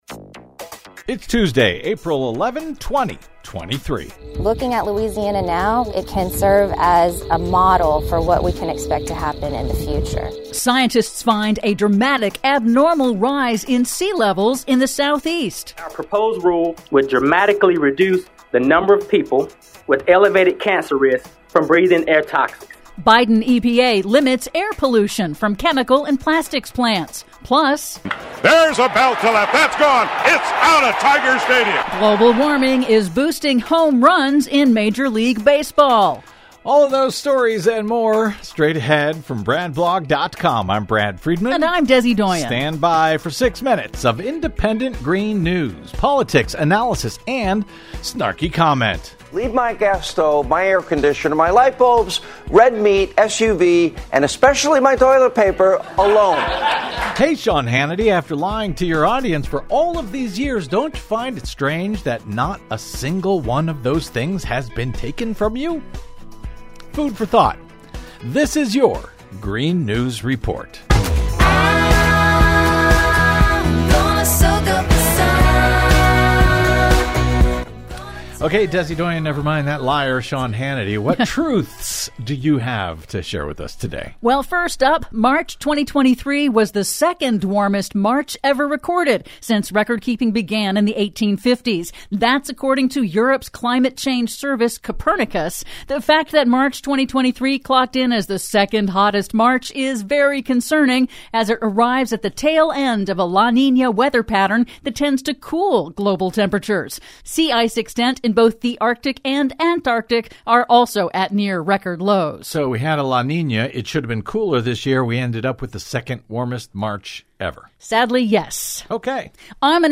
IN TODAY'S RADIO REPORT: Scientists find dramatic, abnormal rise in sea levels in U.S. Gulf, Southeast; Biden EPA limits air pollution from chemical and plastics plants; March 2023 was the second hottest March ever recorded; PLUS: Global warming is boosting home runs in Major League Baseball... All that and more in today's Green News Report!